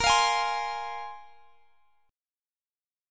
Bb7sus2 Chord
Listen to Bb7sus2 strummed